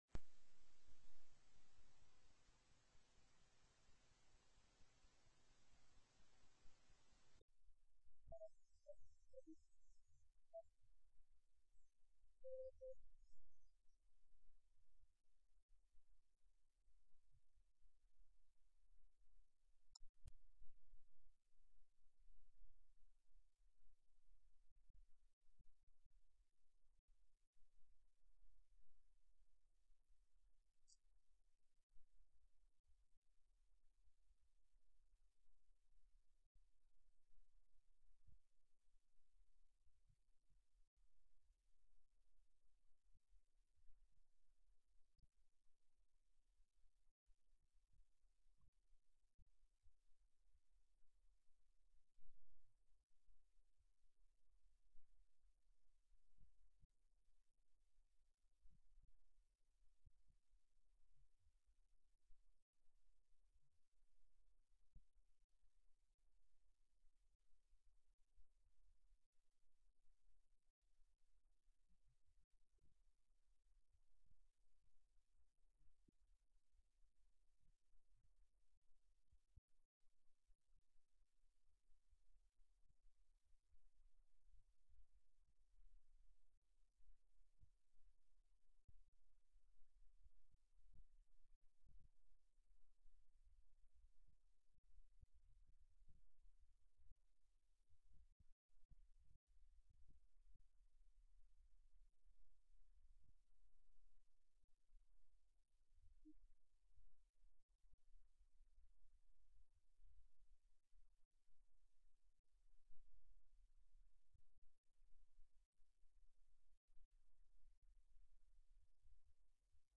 04/03/2008 08:30 AM House FINANCE
PRESENT VIA TELECONFERENCE